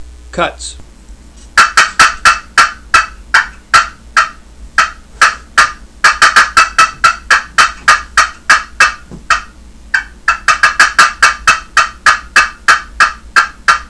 Listen to 14 seconds of cutts
wwpushpincutts14.wav